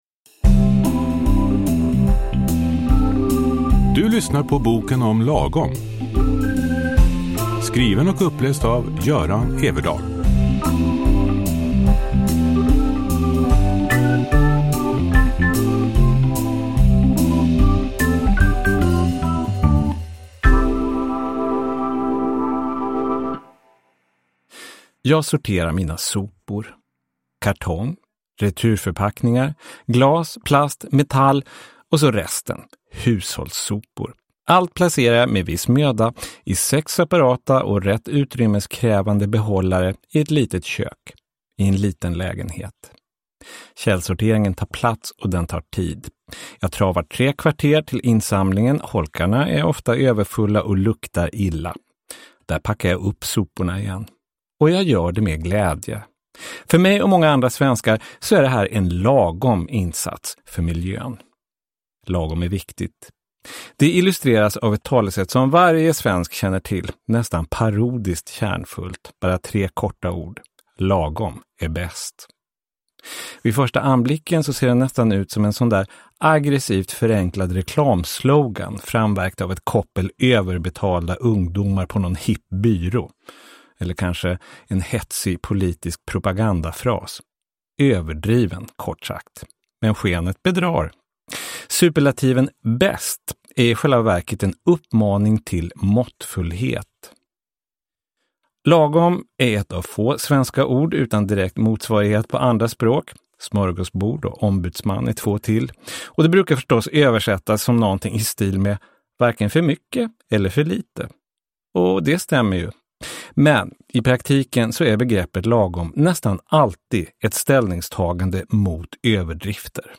Uppläsare: Göran Everdahl